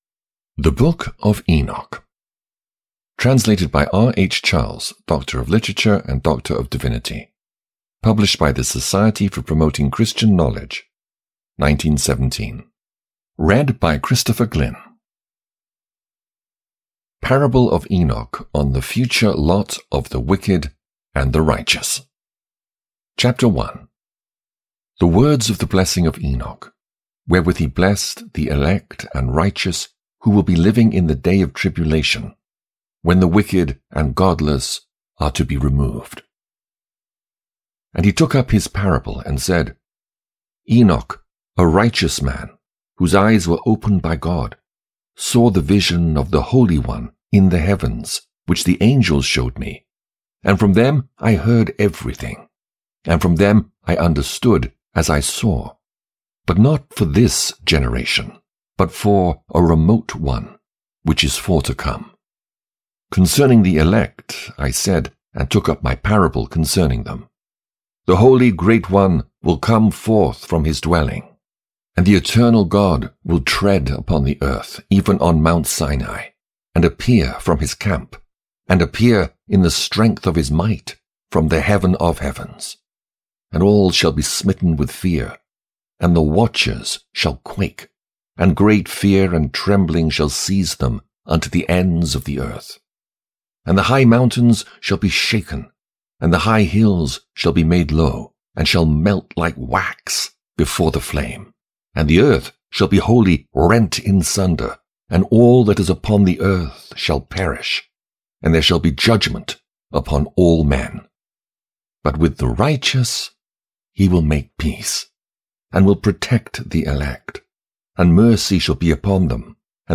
The Book of Enoch (EN) audiokniha
Ukázka z knihy